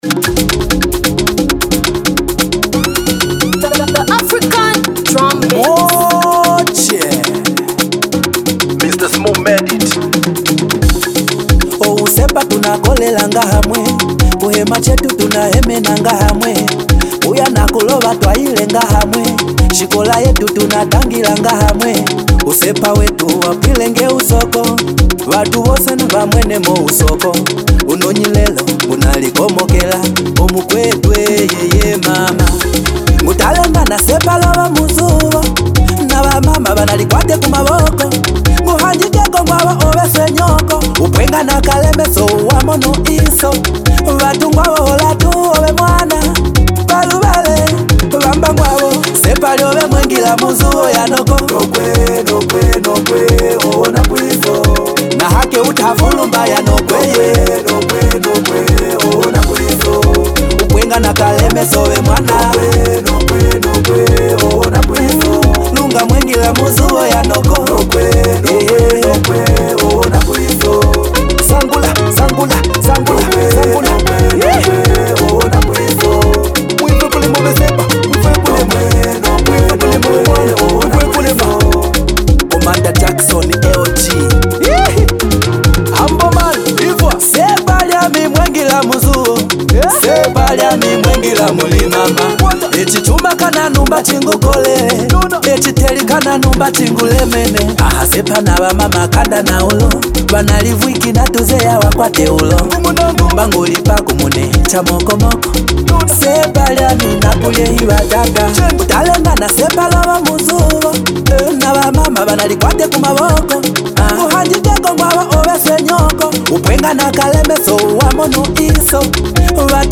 The flavor of afro lulu music worldwide....